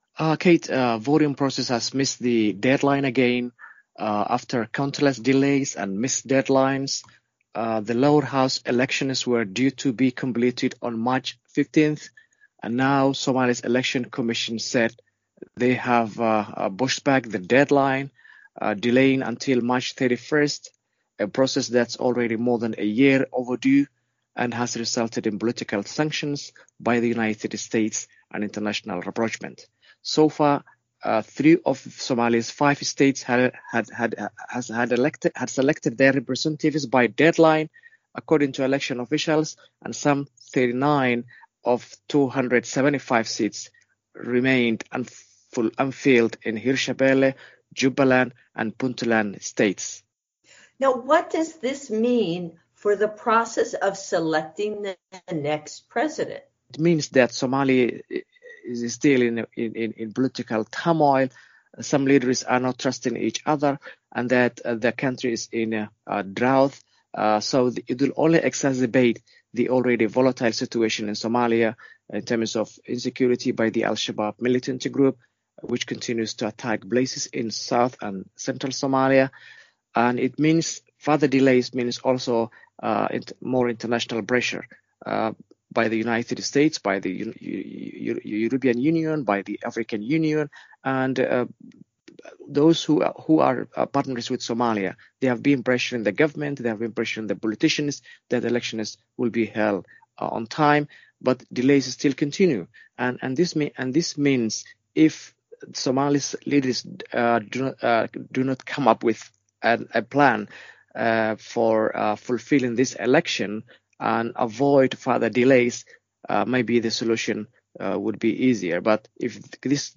Three of Somalia’s five states missed deadlines for electing members of parliament this week.